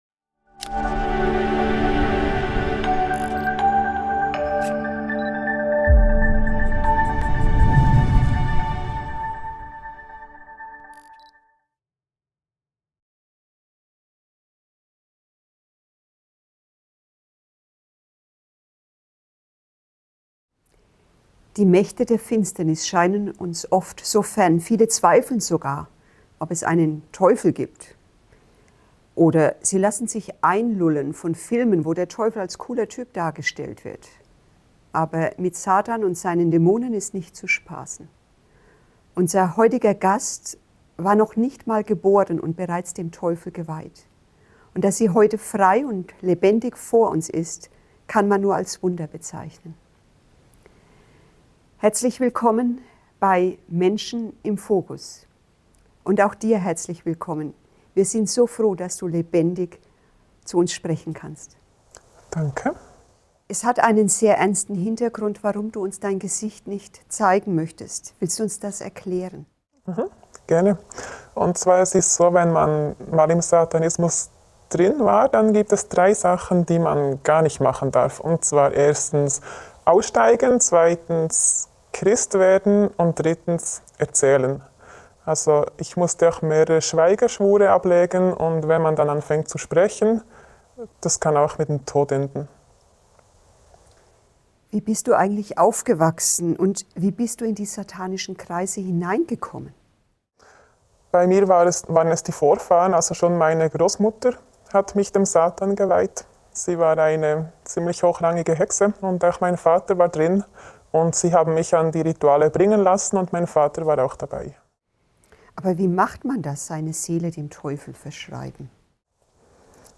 Doch mit Satan und seinen Dämonen ist nicht zu spaßen. Unser heutiger Gast war noch nicht mal geboren und schon dem Teufel geweiht. Das er heute vor uns sitzt, kann man nur als Wunder bezeichnen.